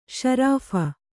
♪ śarāpha